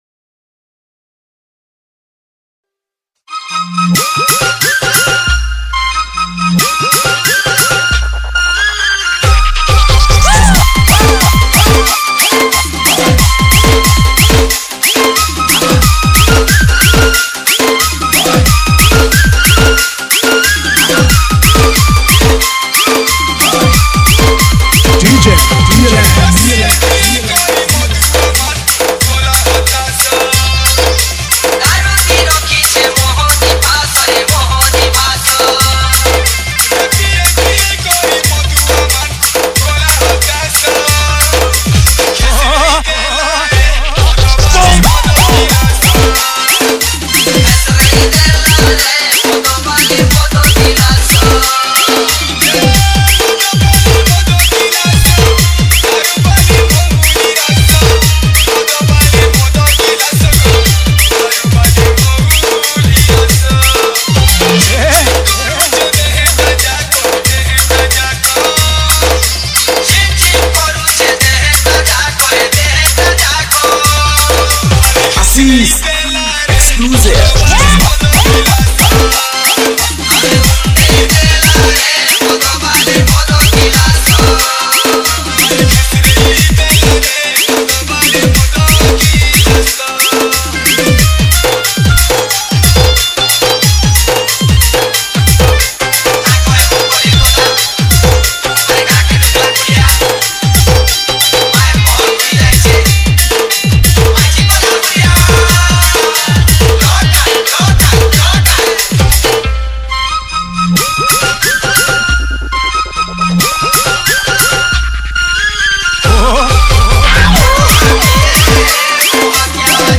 SAMBALPURI ROMANTIC DJ REMIX